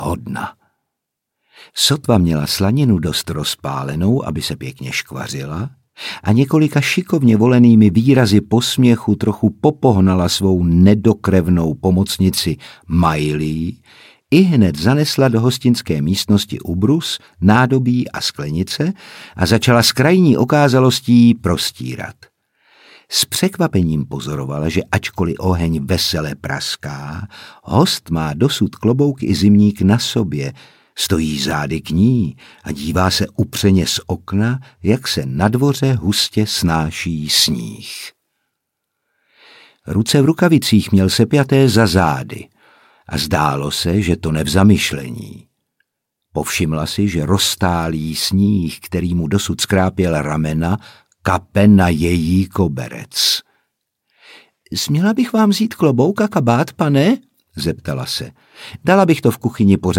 Audiobook
Read: Otakar Brousek Jr.